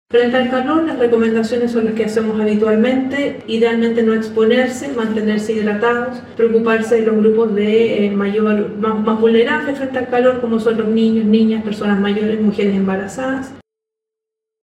cu-ola-de-calor-directora-senapred.mp3